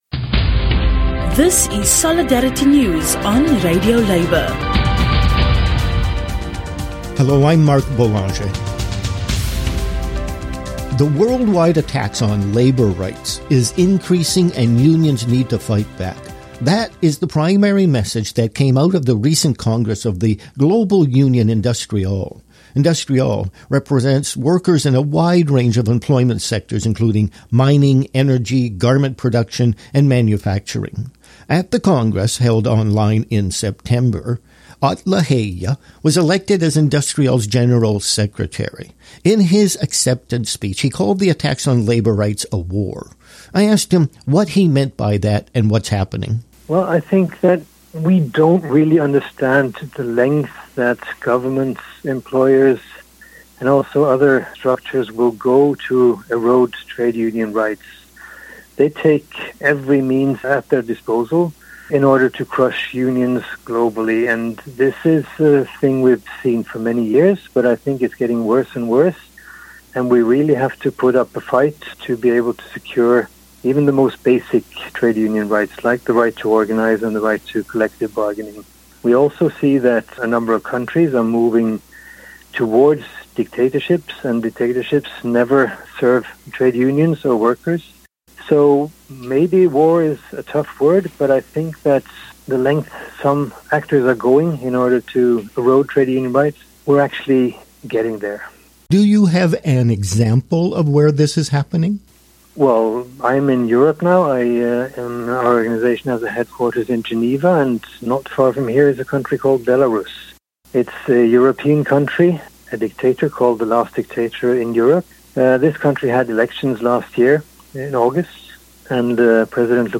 There is a war on labour rights all over the world. The global union industriALL has a four-year action plan to confront the situation. An interview